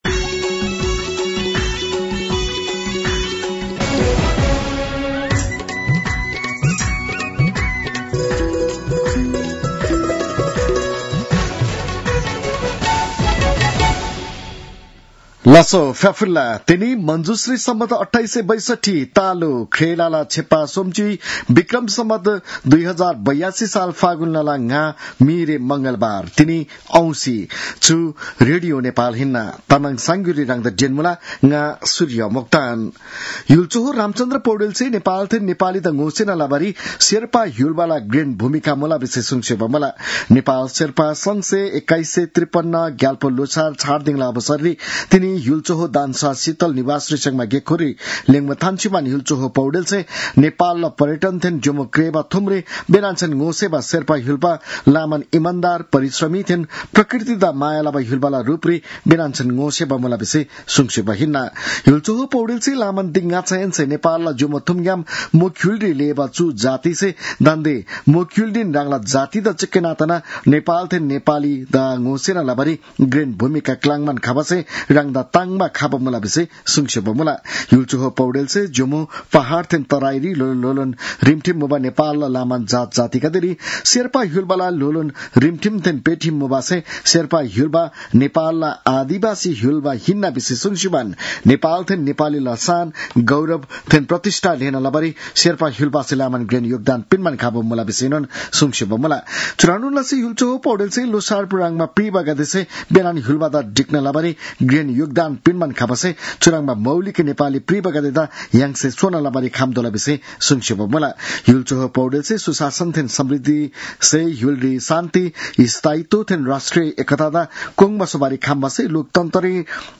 तामाङ भाषाको समाचार : ५ फागुन , २०८२